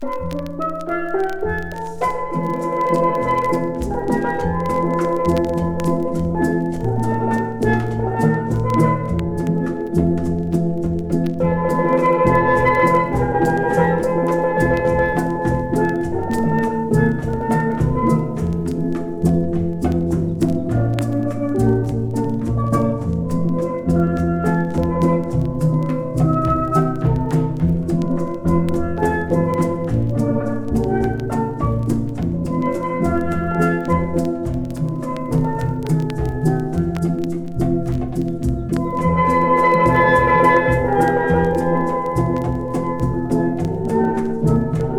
スティール・パンという楽器の魅力が溢れ、心地良い音が響く爽快な1枚。